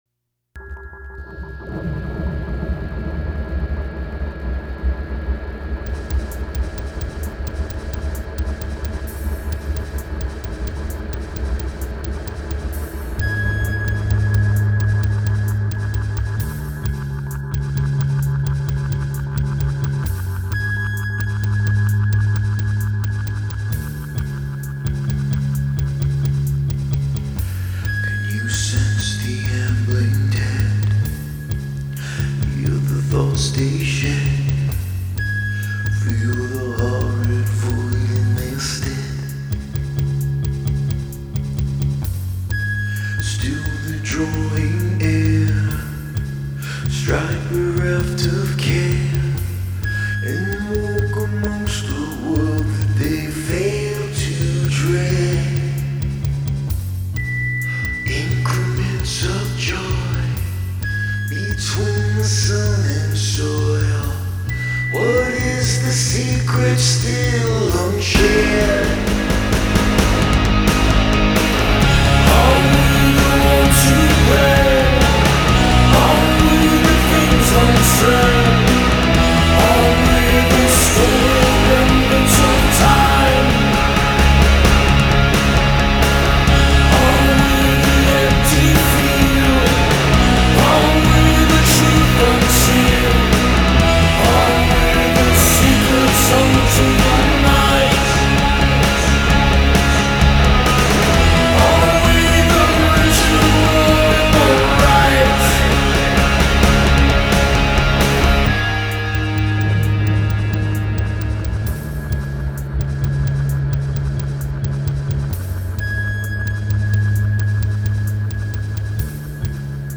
New York post-Punk/Darkwave duo via their new album
guitar and vocals
bass guitar